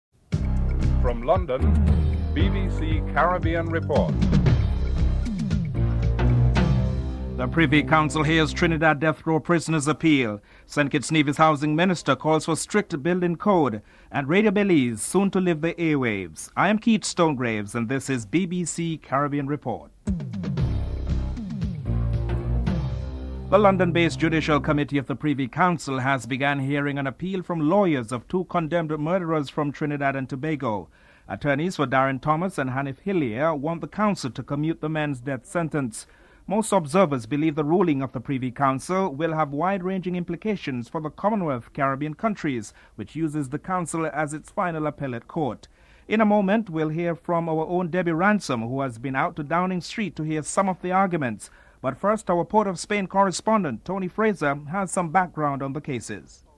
Housing Minister Timothy Harris is interviewed (10:52-12:55)